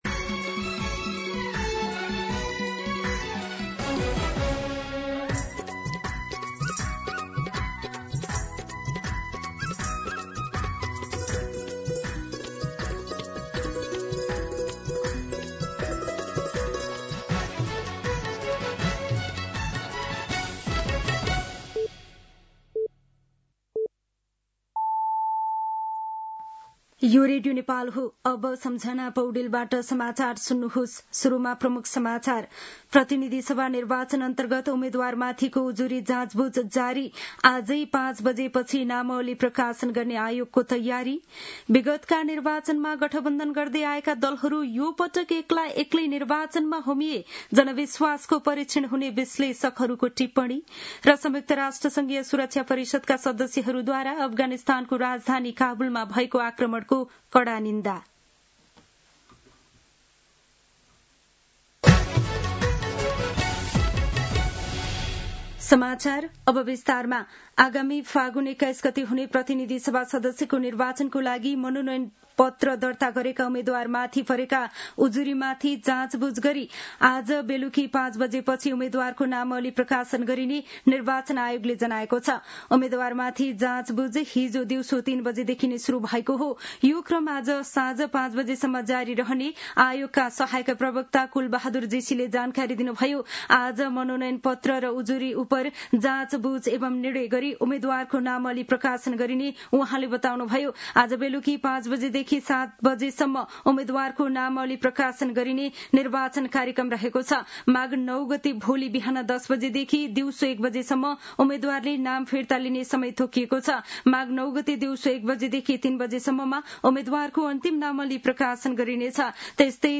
दिउँसो ३ बजेको नेपाली समाचार : ८ माघ , २०८२
3-pm-News-10-8.mp3